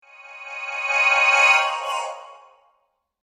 Звуки вспышки
6. Необычное явление: светящаяся щель в двери